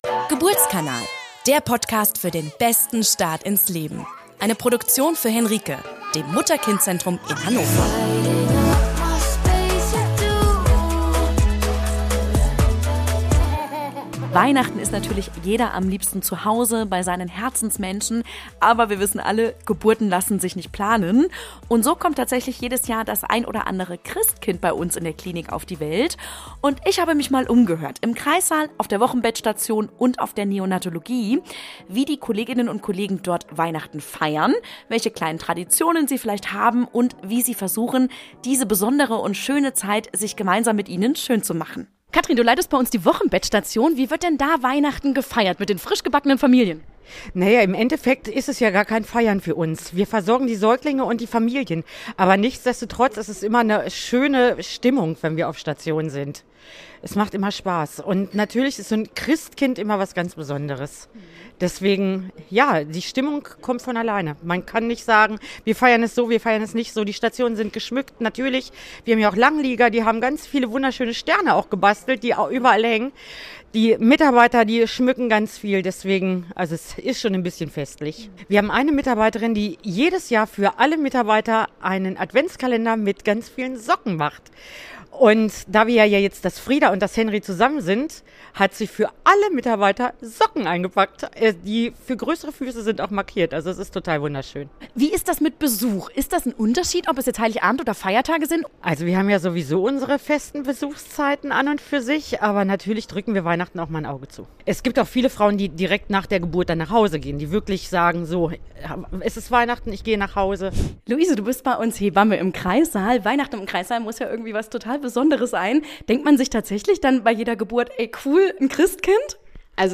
Experten aus dem Kreißsaal, der Wochenbettstation und Neonatologie berichten